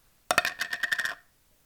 tin+lid+putting+down.aiff